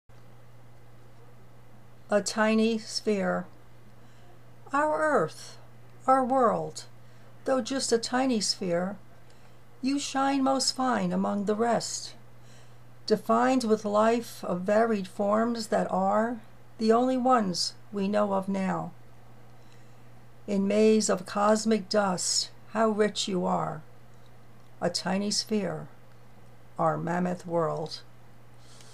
with a lovely voice to boot.. love the tiny sphere against a mammoth world.. big congrats on your fine win in my contest.. huggs